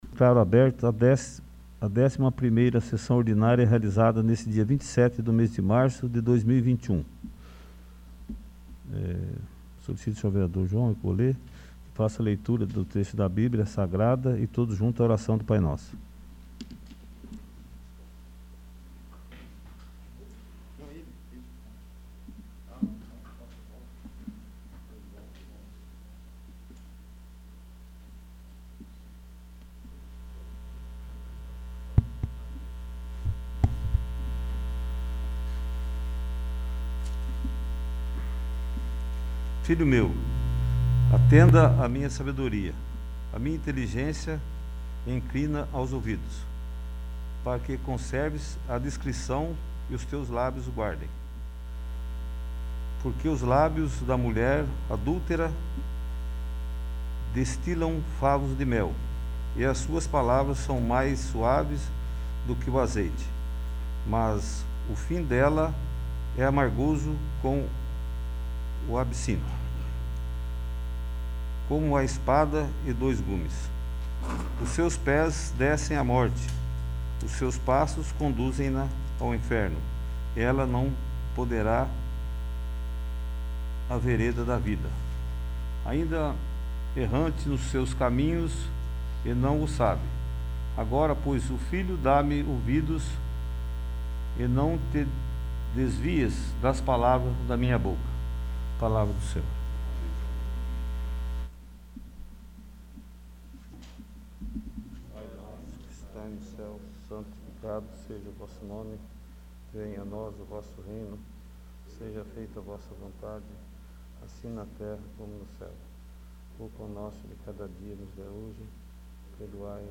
11º. Sessão Ordinária